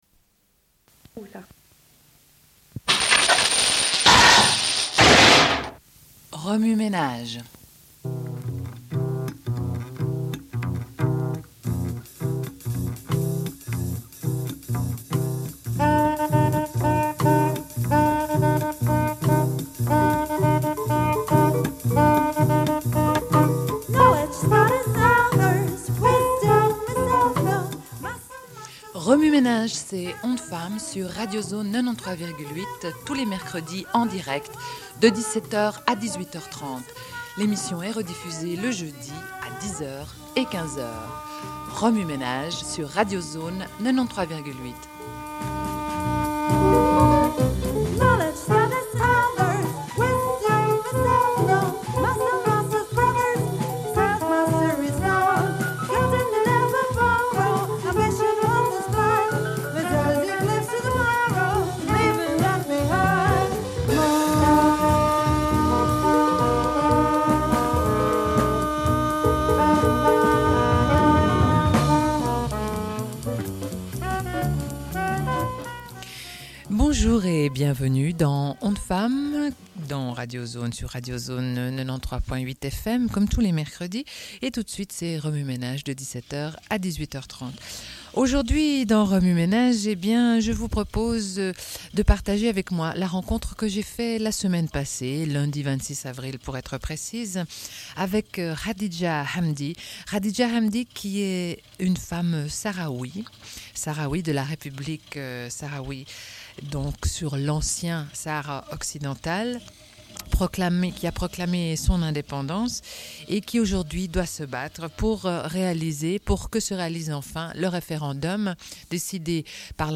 Une cassette audio, face A31:24